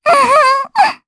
Luna-Vox_Happy1_jp.wav